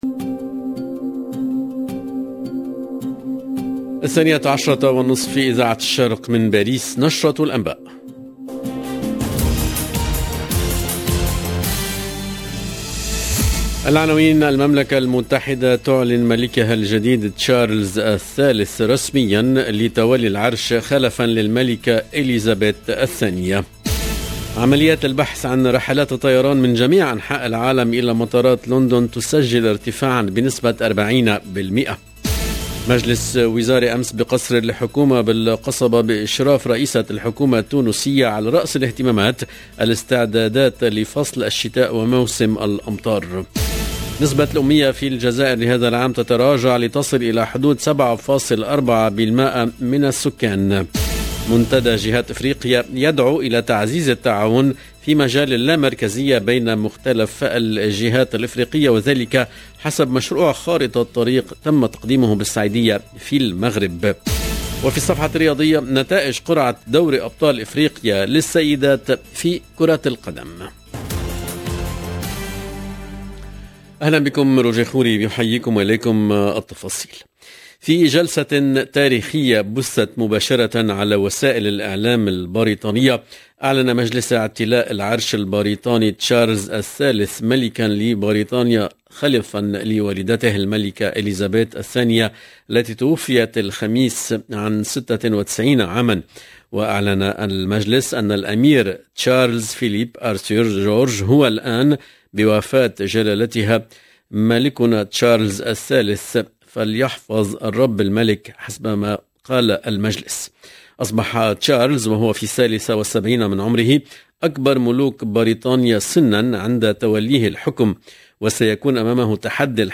LE JOURNAL EN LANGUE ARABE DE MIDI 30 DU 10/09/22